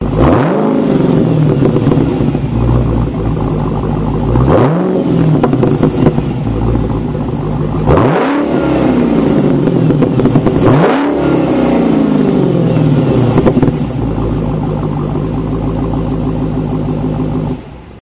dodge-challenger-srt-hellcat-revving.mp3